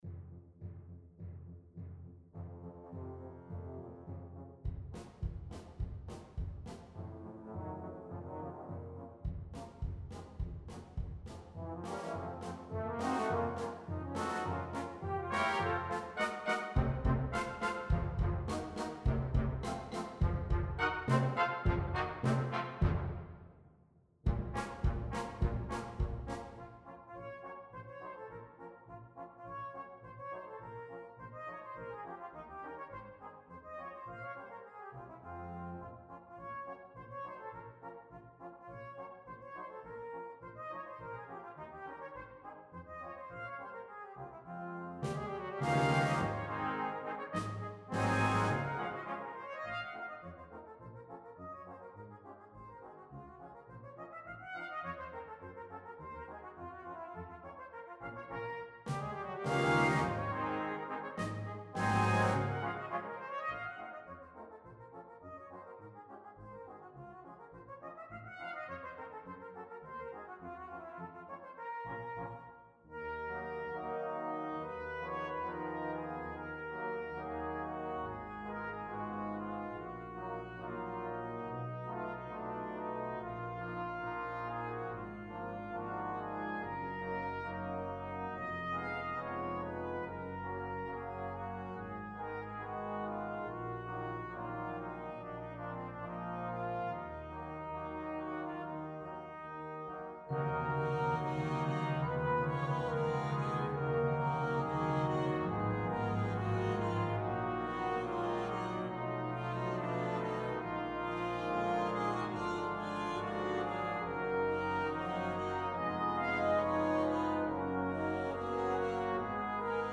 Besetzung: Cornet Solo & Brass Band